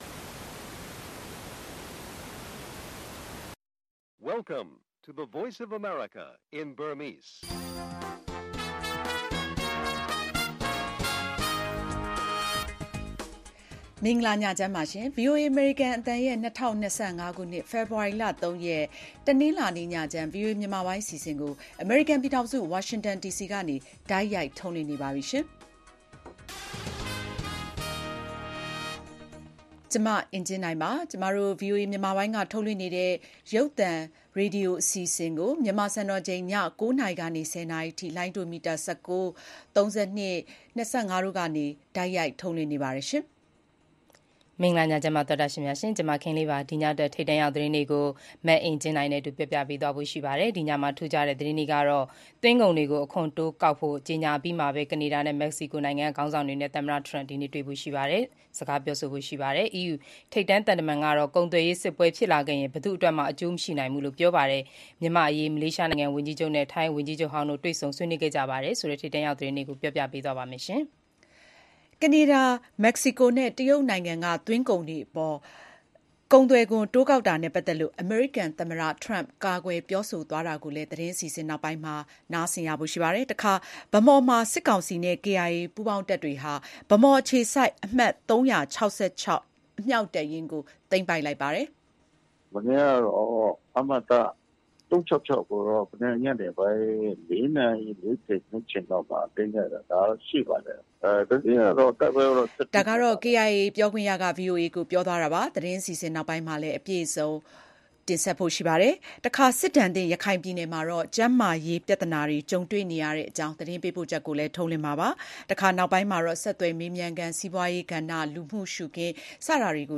အမေရိကန် သွင်းကုန်ခွန် တိုးကောက်မယ့် အစီအစဉ် အစုရှယ်ယာဈေးကွက် ရိုက်ခတ်၊ ဗန်းမော်မှာ စစ်ကောင်စီနဲ့ KIA ပူးပေါင်းတပ်တွေကြား တိုက်ပွဲဆက်လက်ပြင်းထန် စတာတွေအပြင် သမ္မတထရမ့် အစိုးရရဲ့ အာရှပစိဖိတ်မူဝါဒ အလားအလား ဆက်သွယ်မေးမြန်းခန်း၊ စီးပွားရေး၊ လူမှုရှုခင်း သီတင်းပတ်စဉ် အစီအစဉ်တွေကို တင်ဆက်ထားပါတယ်။